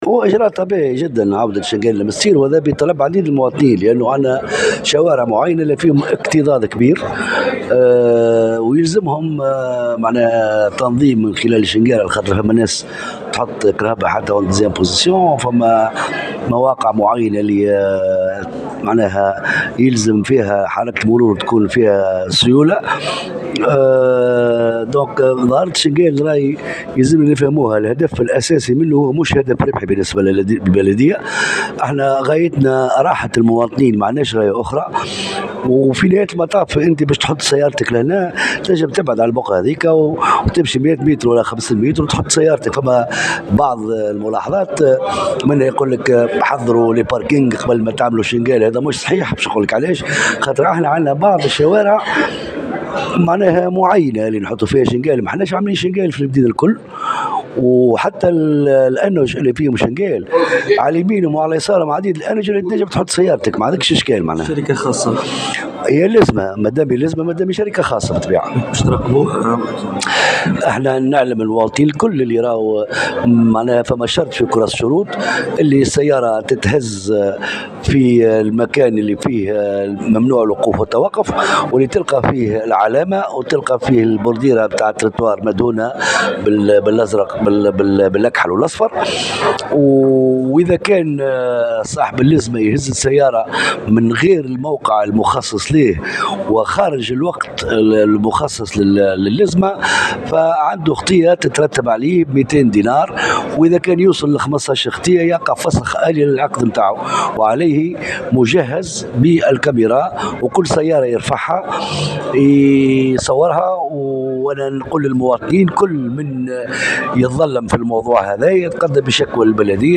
وأكد مرزوق في تصريح لمراسل الجوهرة أف أم، أن عودة "الصابو" و"الشنقال" بعد نحو عام ونصف، جاء بطلب من المواطنين بسبب سلوكيات بعض السائقين الذين يتسببون في تعطيل حركة المرور، مشددا على ان غاية البلدية من القرار ليس ربحيا بقدر ماهو راحة المواطن وتسهيل حركة المرور.